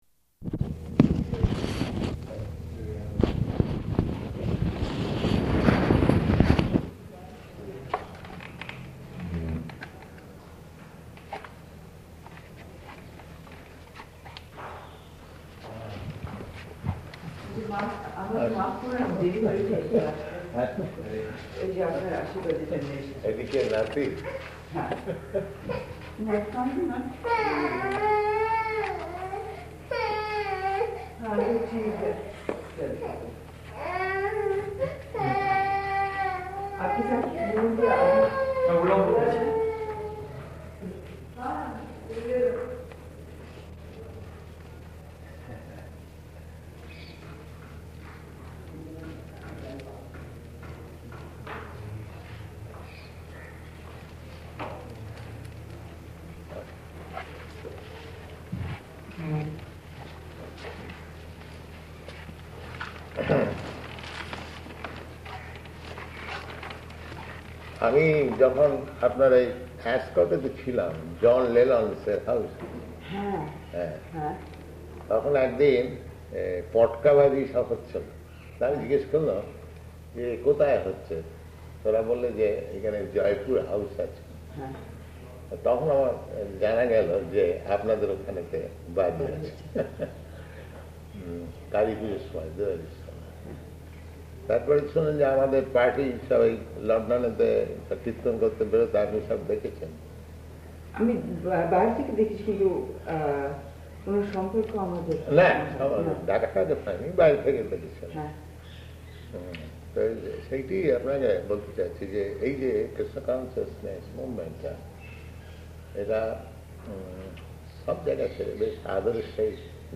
Room Conversation in Bengali
-- Type: Conversation Dated